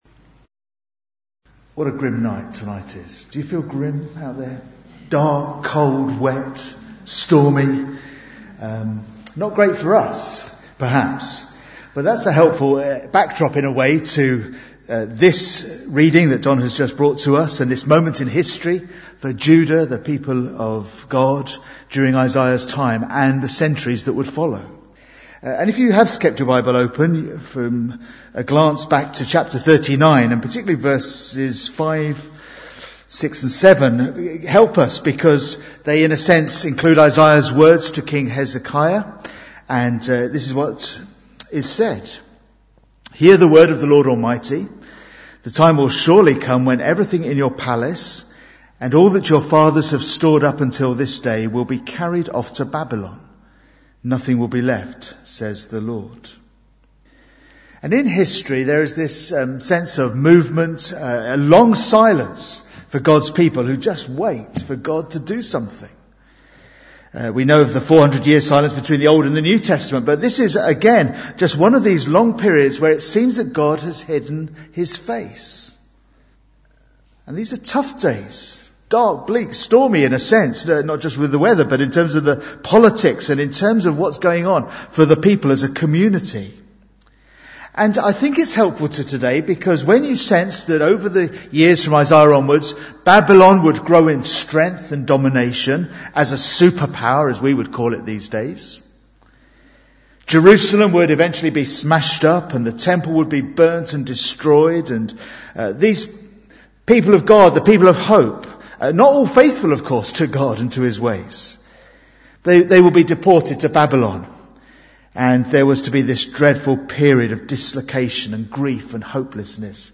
Bible Text: Isaiah 40:1-11 | Preacher